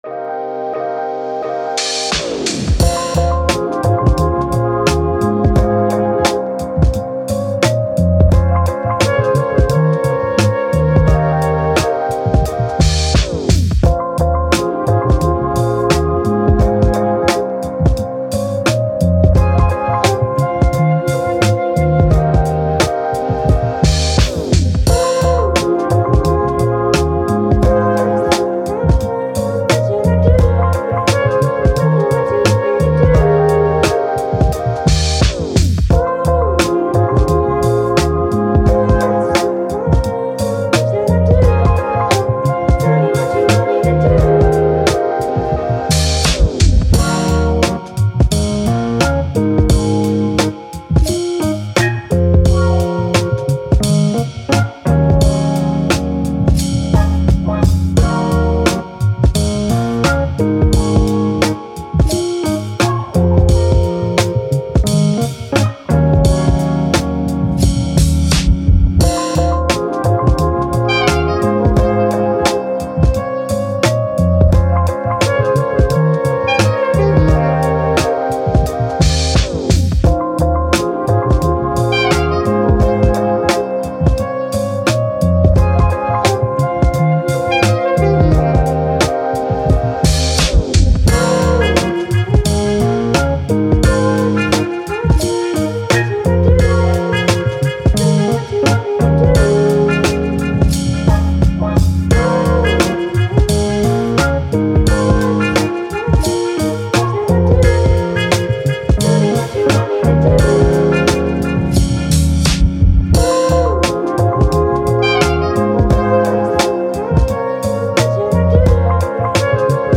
Hip Hop, Instrumental, Upbeat, Jazz, Action